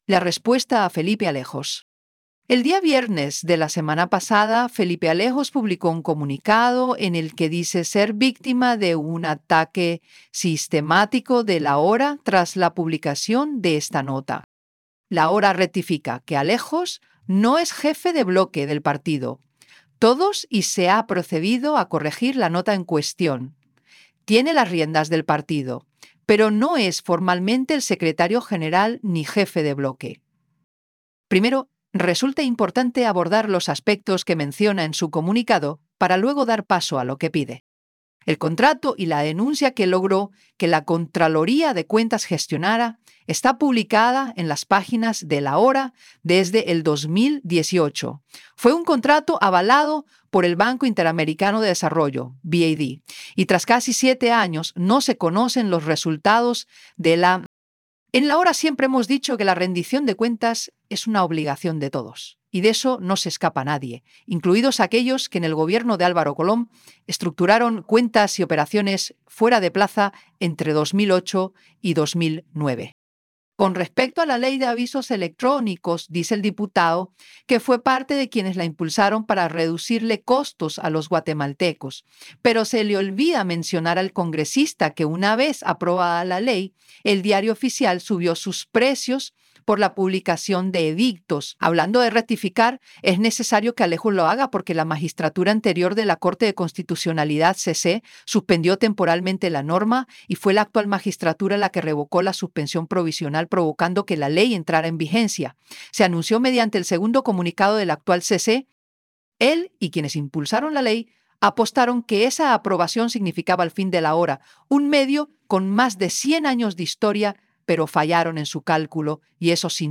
Editorial_-PlayAI_La_respuesta_a_Felipe_Alejos.wav